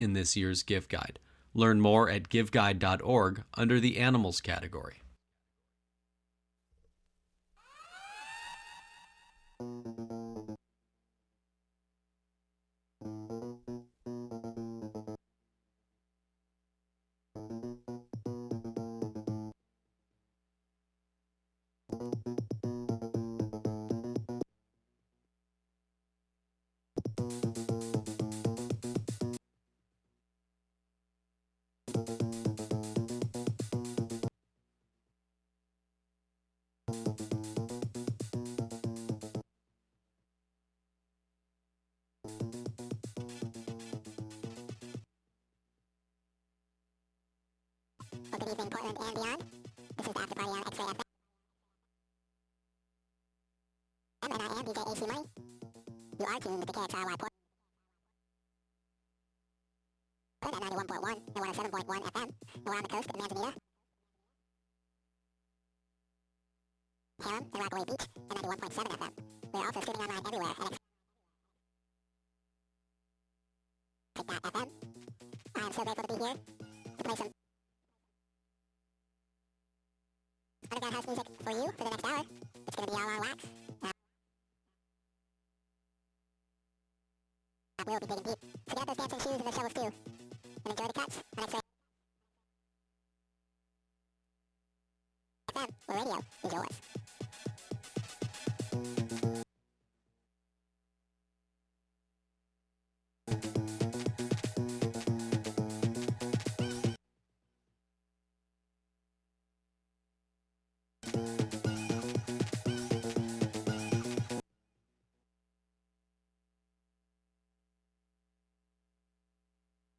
Fat basslines and deep grooves.